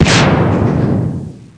00546_Sound_fire.mp3